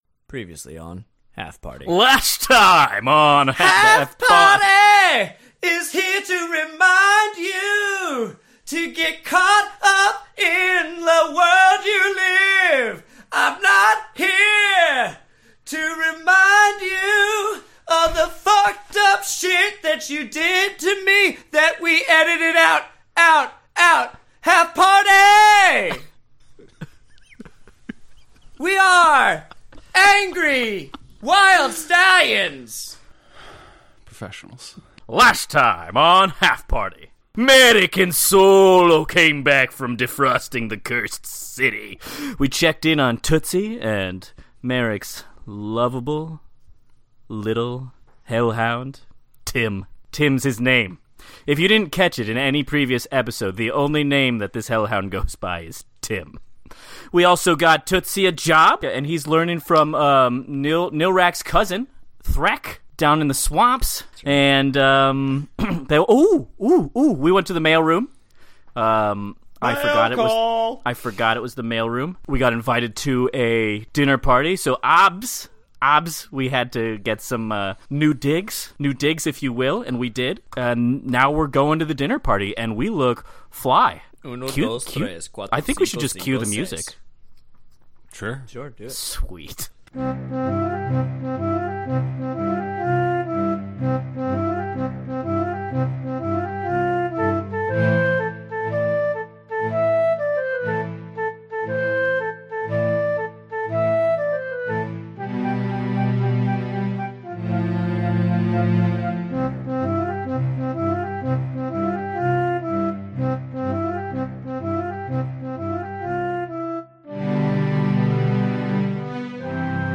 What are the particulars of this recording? Well this one is the first time we have a rookie editor, so be aware of varying audio levels. Perhaps start the episode a little lower, that intro is a little, how shall we say, raucous.